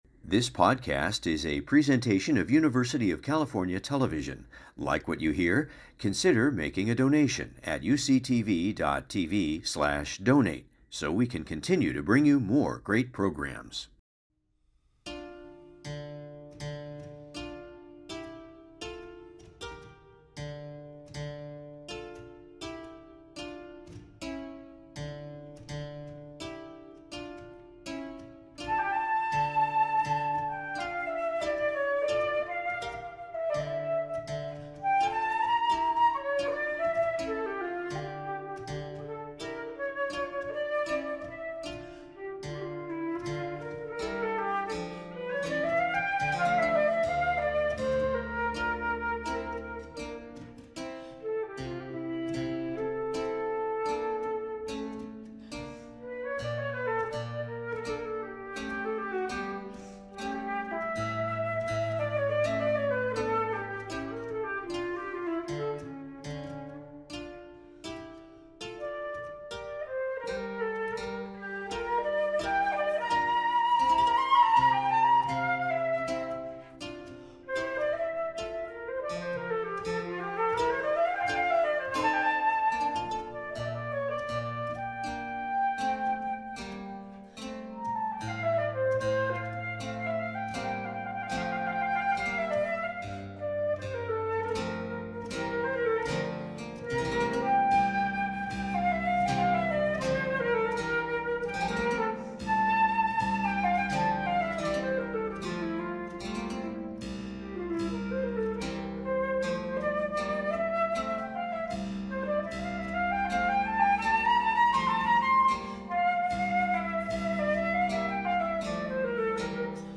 beautiful music